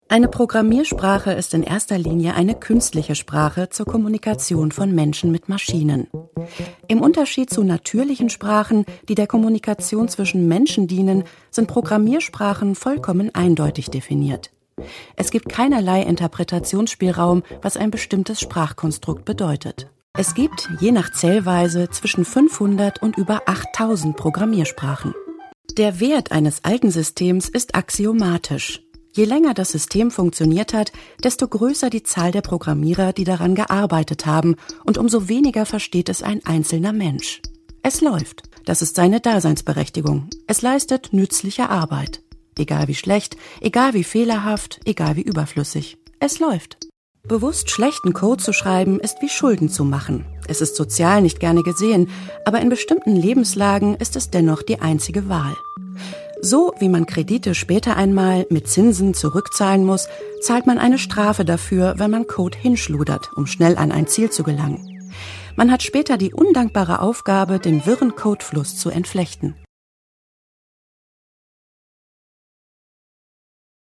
Ihre sanfte, klare und warme Stimme wurde bereits für Werbung, zahlreiche Hörspiele und Features, u. a. für Der Hörverlag, Ravensburger und Europa, aber auch für Radioproduktionen von Deutschlandfunk, RBB, Deutschlandradio Kultur, Hessischer Rundfunk oder den WDR eingesetzt.
Sprechprobe: Industrie (Muttersprache):